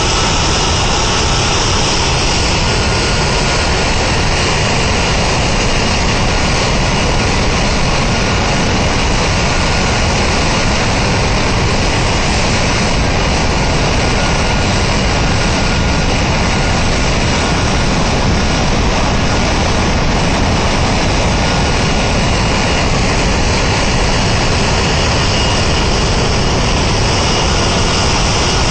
cfm-spoolCloseRear.wav